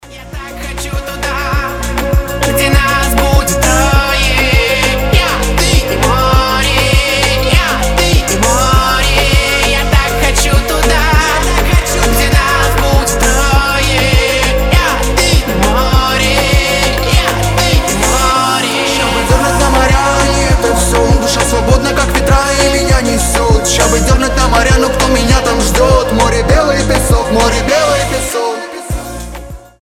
поп
мужской голос
рэп
летние
мечтательные